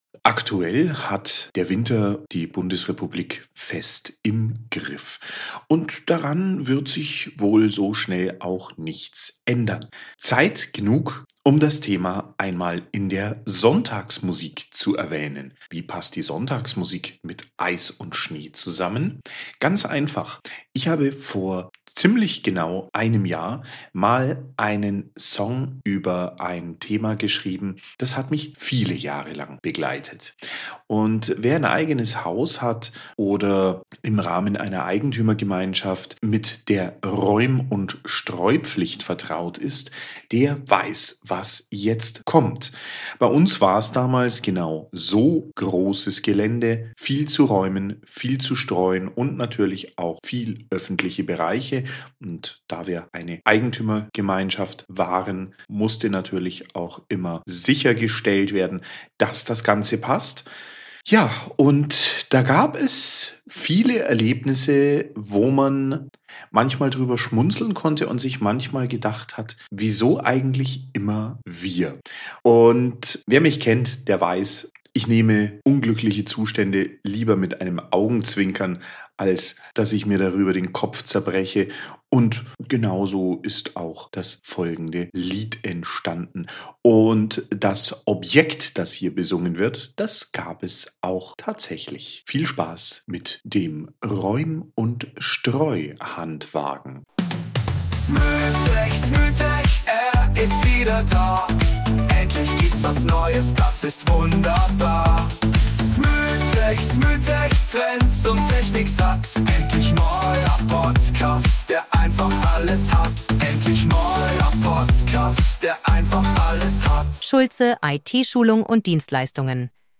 Heute hören wir ein Lied darüber in der Sonntagsmusik.